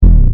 overloadbd.mp3